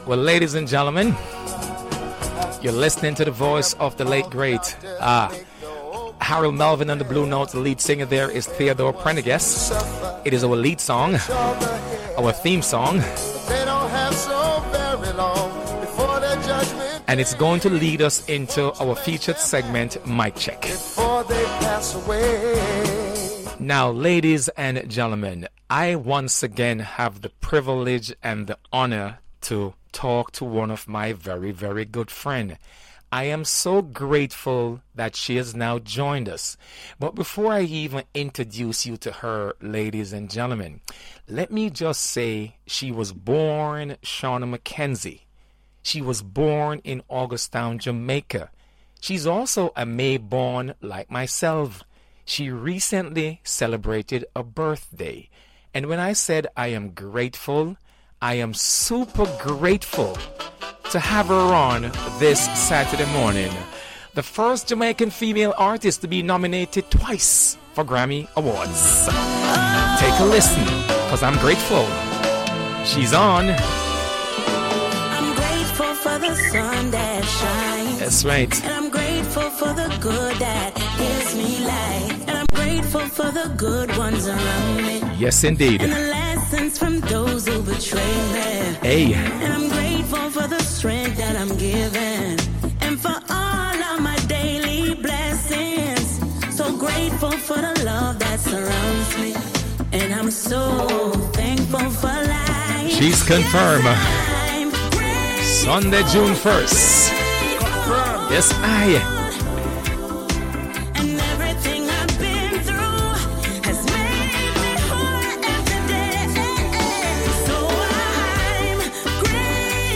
Groovin Radio Mic Check With Westchester Festival feat Artist Etana – Groovin Radio NYC
Groovin Radio Mic Check With Westchester Festival feat Artist Etana.mp3